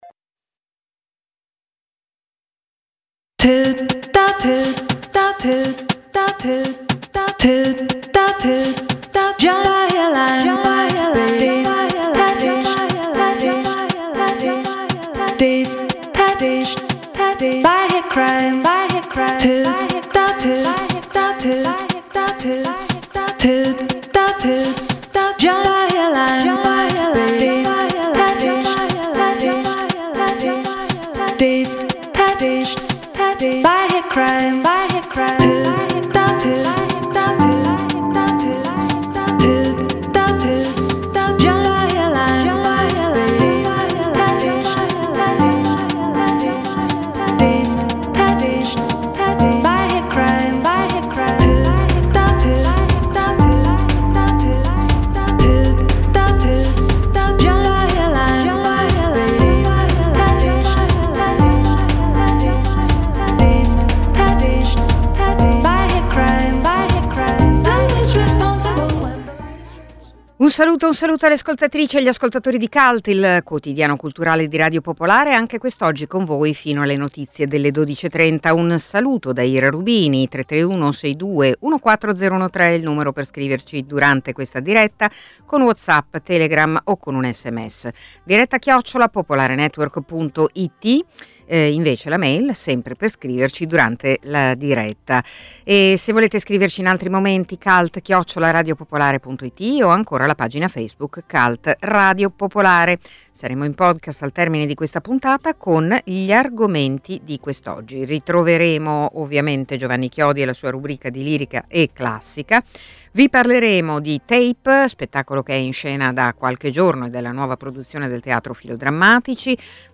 intervista la regista Ildiko Enyedi